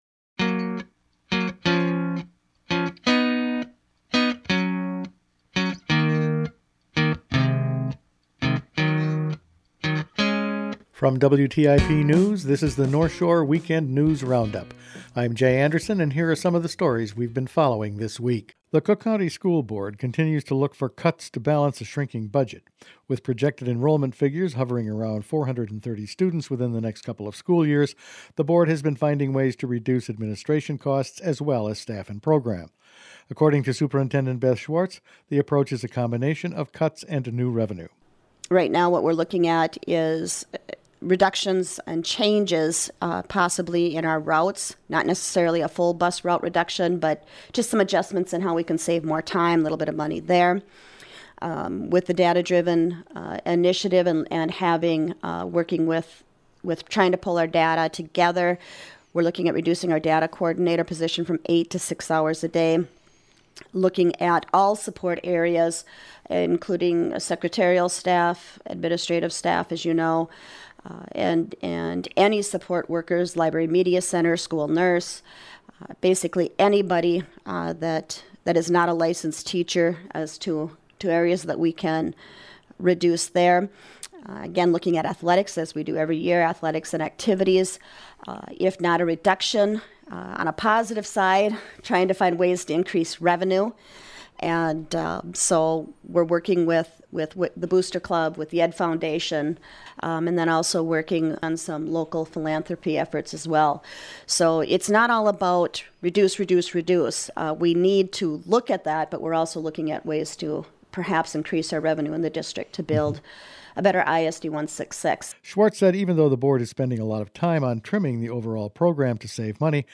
Weekend News Roundup for May 21